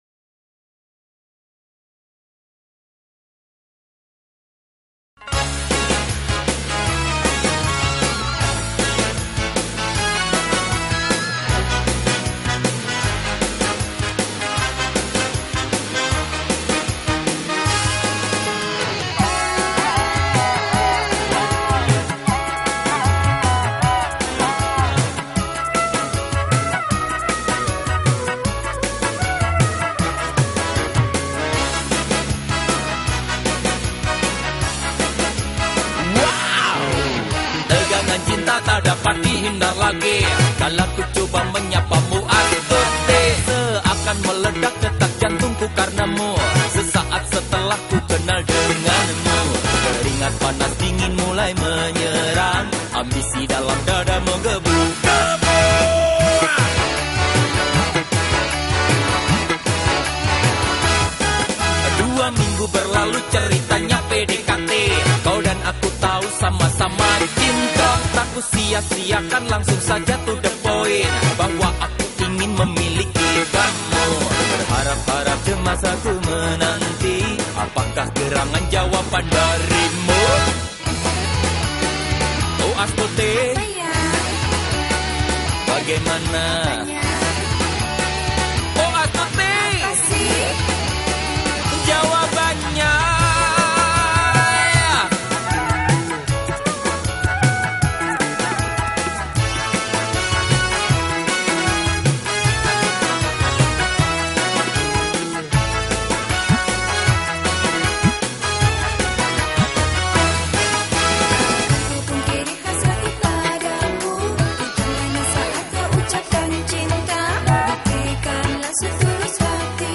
dangdut